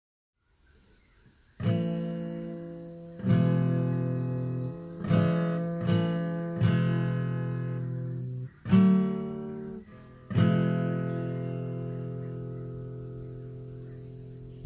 吉他弹奏
描述：基本上与其他吉他录音相同，除了不同的和弦和弹奏模式。在原声吉他上安静地演奏和弦，标准调音，无变调演奏。
Tag: 声学 弹拨 吉他弦 吉他 和弦编排